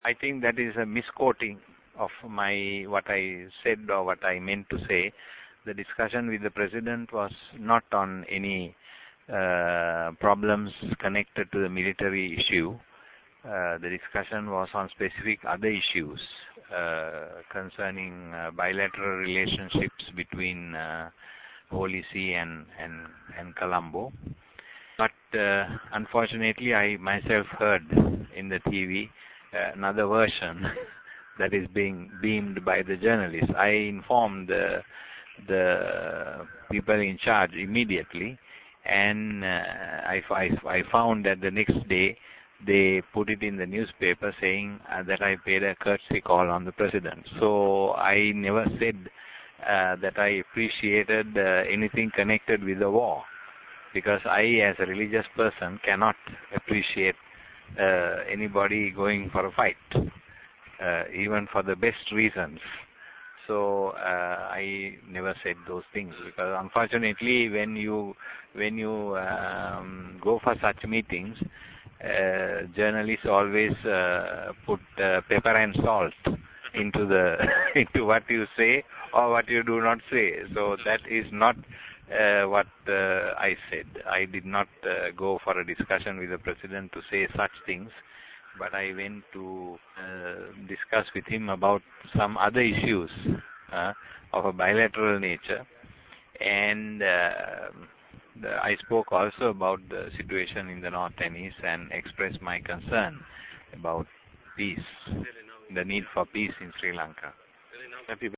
Further referring to his meeting with Mr. Mahinda Rajapaksa, Bishop Malcolm Ranjith told the press in Ki'linochchi that his meeting with the Lankan president dealt with "bilateral relationships between Holy See and Colombo."
Voice: Bishop Malcolm Ranjith
bishop_malcolm_ranjith.mp3